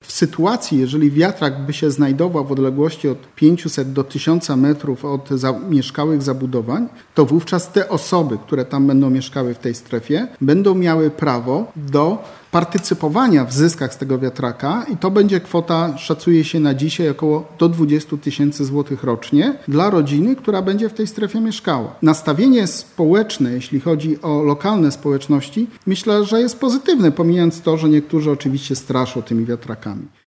Ustawa wiatrakowa, która trafiła niedawno na biurko prezydenta RP, była tematem dzisiejszej (13.08) konferencji zamojskiego posła Polski 2050 – Sławomira Ćwika. Parlamentarzysta podkreślał, że energetyka wiatrowa jest dziś najtańszym sposobem na pozyskanie taniej, zielonej energii, a gminy, w których staną wiatraki, będą miały duże wpływy z tytułu podatku od nieruchomości.